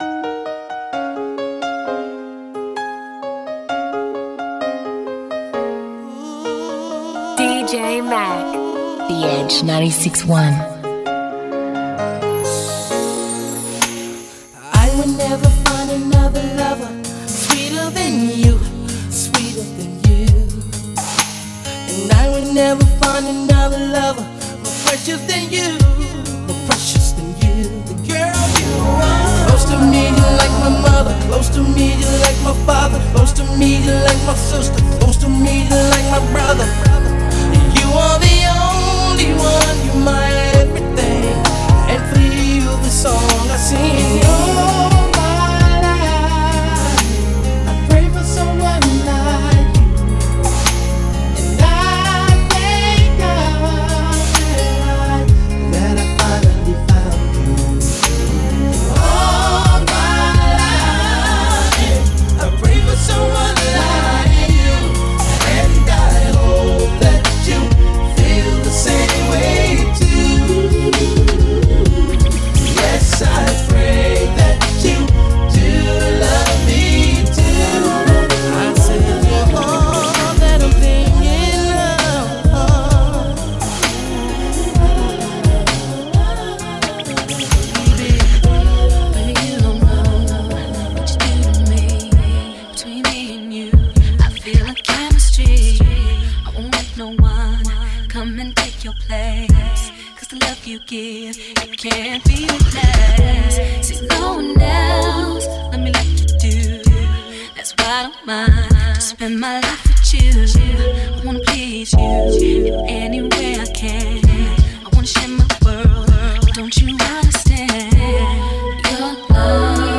Australia’s answer to Old Skool, RNB and New Jack.